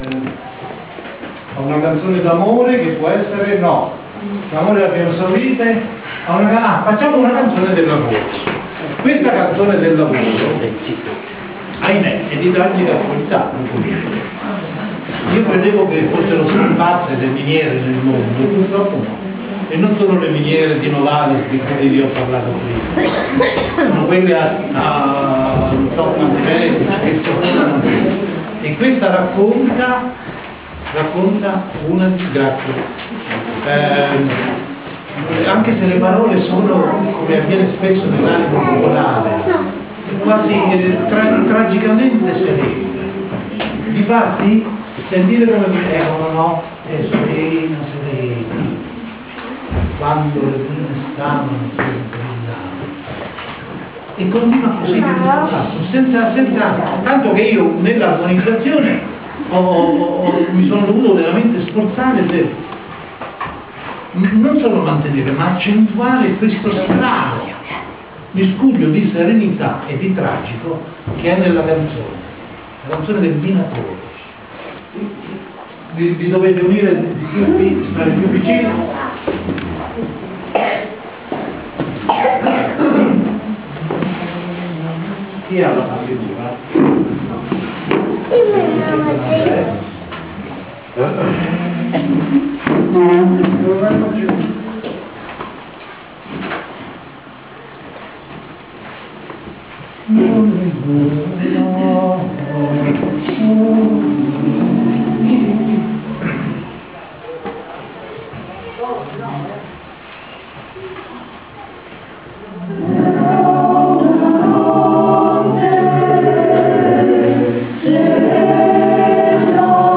Civico Museo d’Arte Moderna di Anticoli Corrado, sabato 13 ottobre 2007